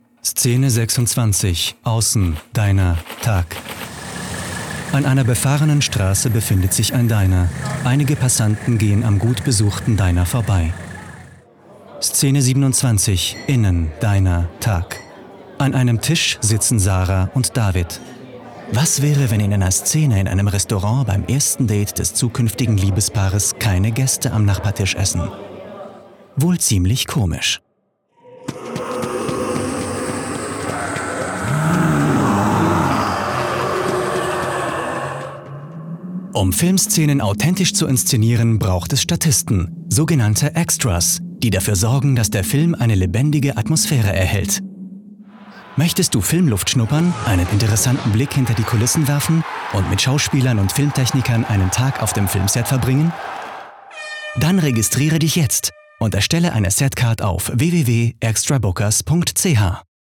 OFF-Kommentar Hochdeutsch (CH)
Schauspieler mit breitem Einsatzspektrum.